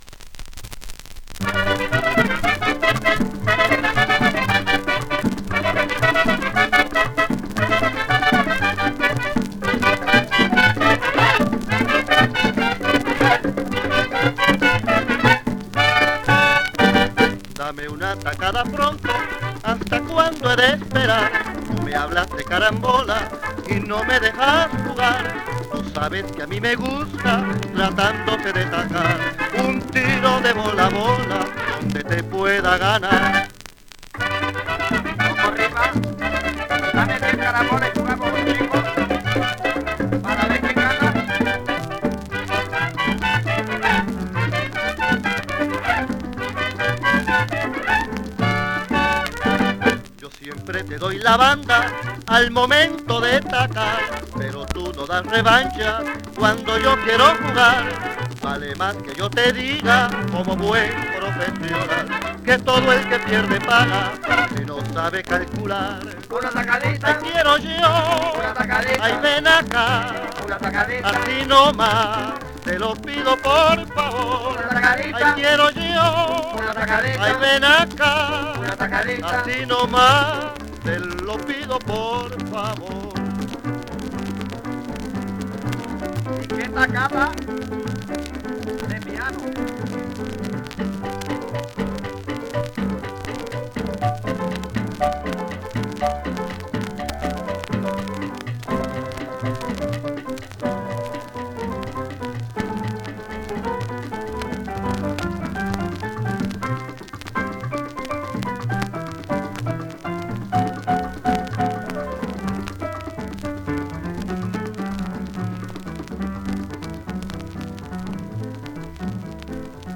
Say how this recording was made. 1 disco : 78 rpm